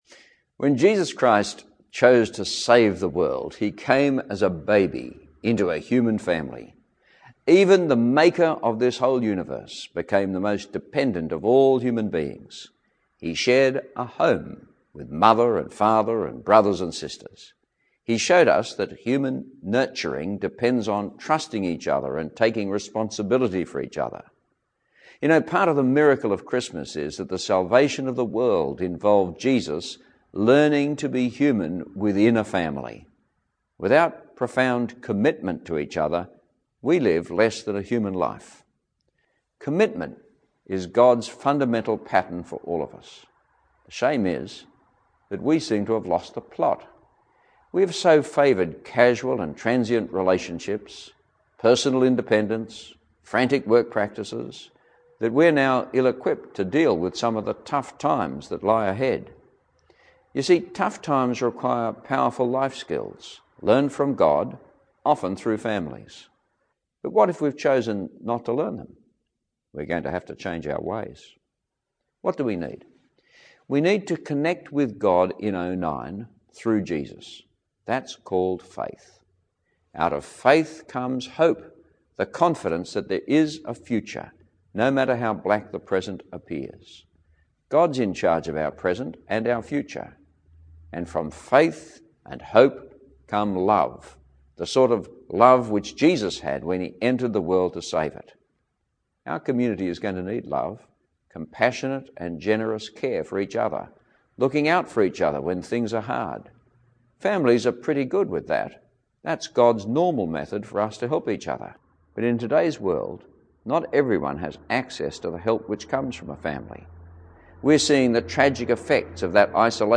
Archbishop Dr Peter Jensen has delivered his 2008 Christmas message, stressing that commitment, compassion and ‘generous care’ will be needed in the year ahead.
Christmas_message_2008.mp3